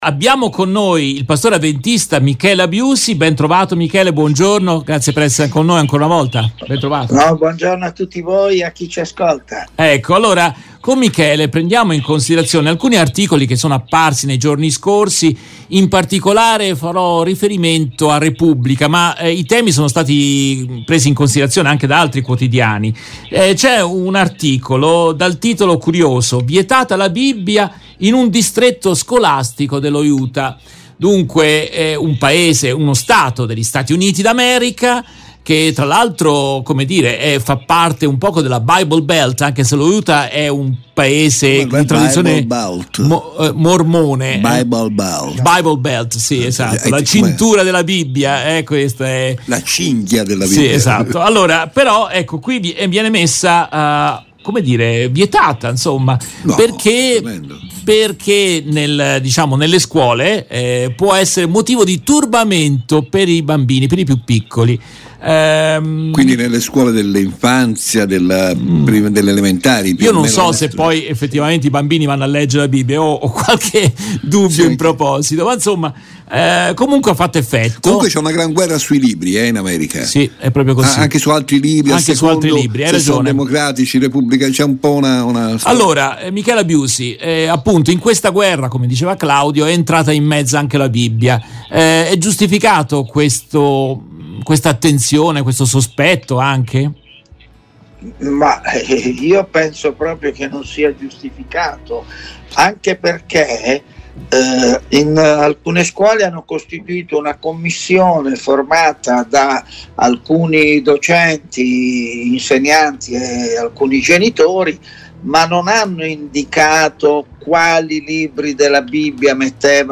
Nel corso della diretta RVS del 06 giugno 2023